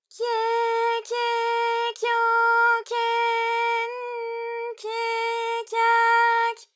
ALYS-DB-001-JPN - First Japanese UTAU vocal library of ALYS.
kye_kye_kyo_kye_n_kye_kya_ky.wav